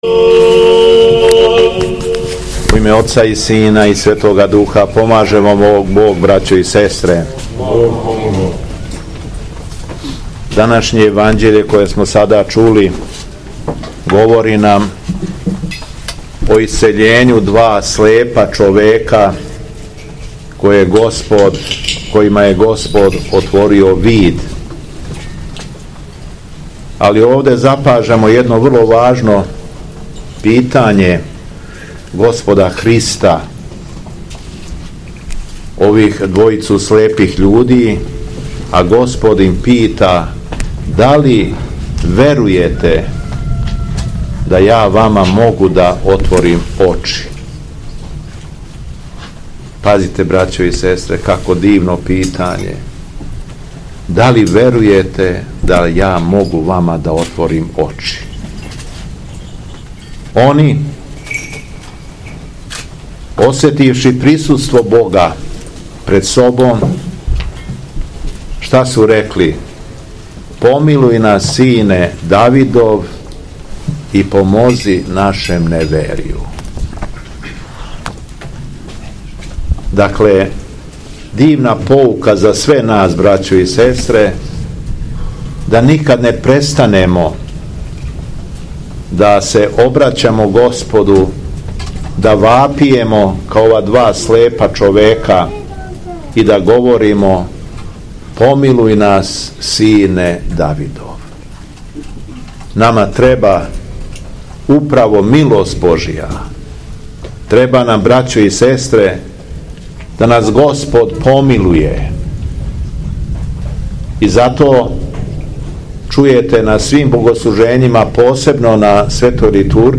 АРХИЈЕРЕЈСКА ЛИТУРГИЈА И ПРОСЛАВА ХРАМОВНЕ СЛАВЕ У ЦРКВИ У БАБАМА - Епархија Шумадијска
Беседа Његовог Високопреосвештенства Митрополита шумадијског г. Јована